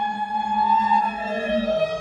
3 Boiling In Dust Clouds B Long.wav